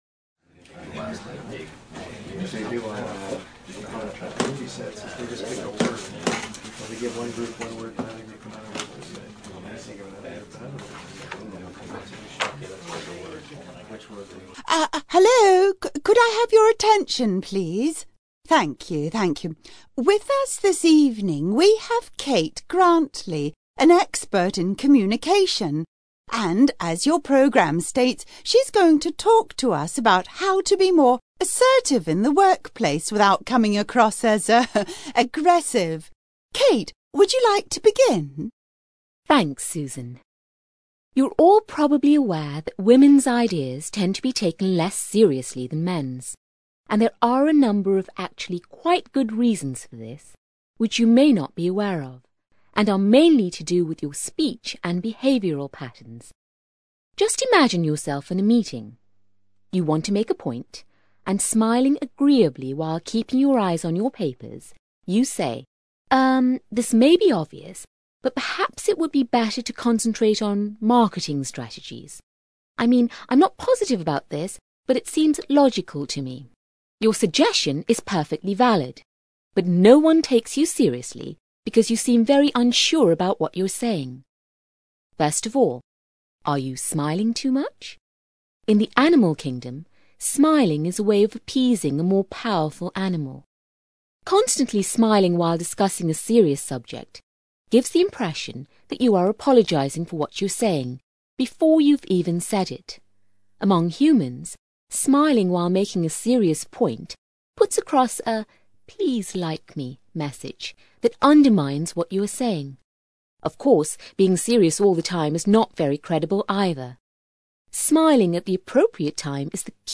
ACTIVITY 14: You will hear part of a talk on behaviour in the workplace.